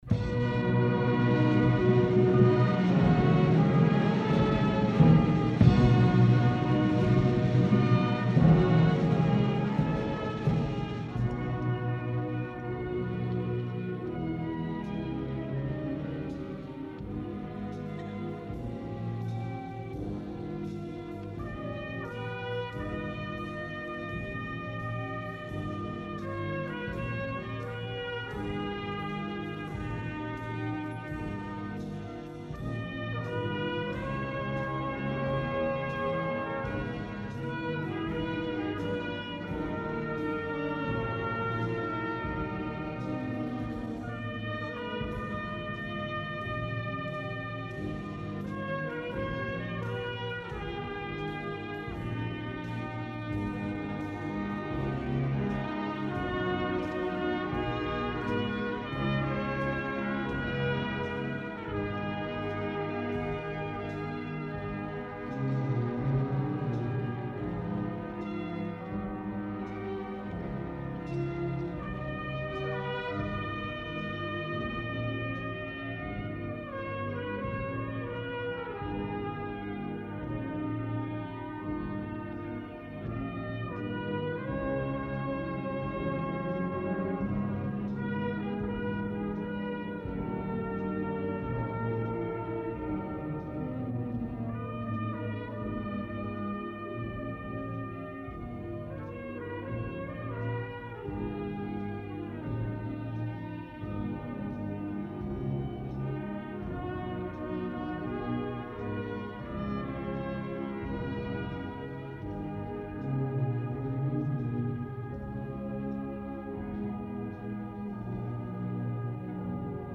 Concert de Sa Fira a l'Esglèsia de la Nostra Senyora de la Consolació